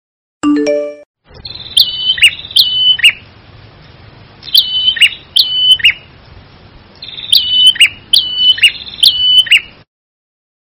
Nada dering Burung Prenjak
Keterangan: Nada dering burung prenjak ini hadir dengan suara khas yang merdu dan alami.
nada-dering-burung-prenjak-id-www_tiengdong_com.mp3